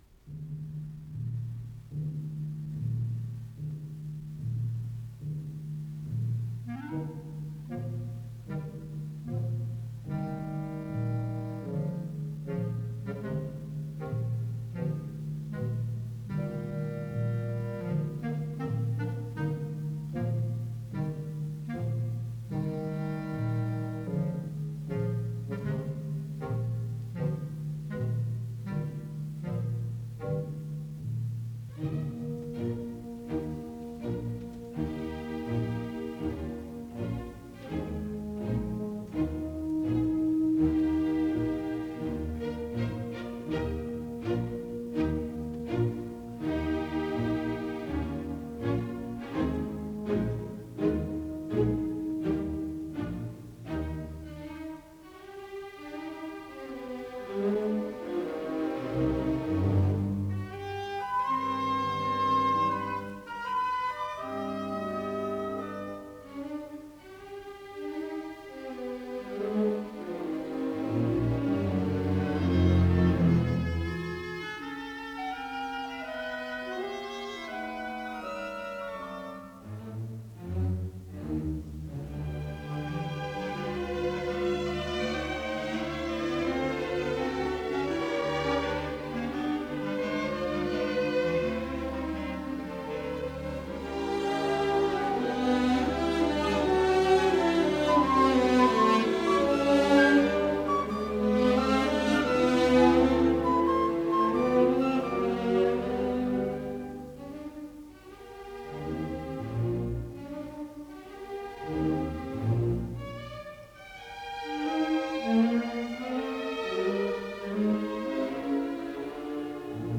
с профессиональной магнитной ленты
Андантино марчиале. Квази модерато.
ИсполнителиГосударственный симфонический оркестр СССР
Дирижёр - Евгений Светланов
ВариантДубль моно